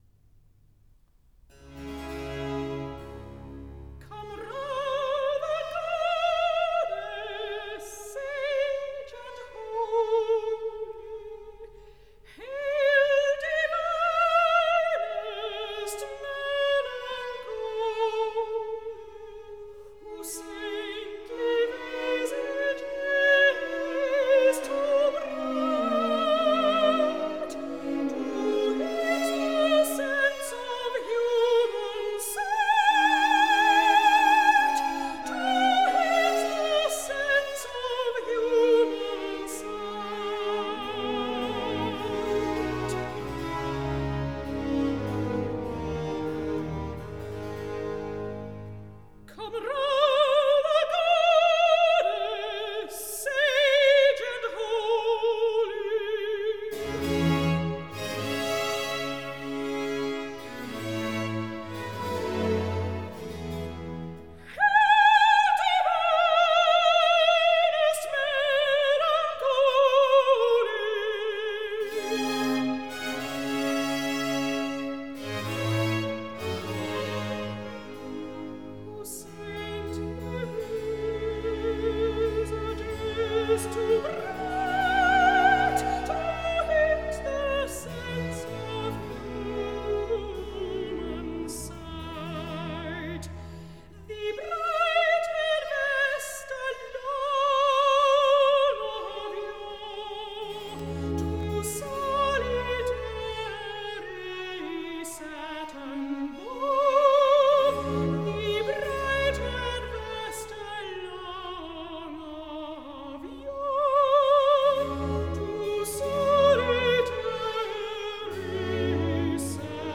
1. Air
Il Penseroso (soprano)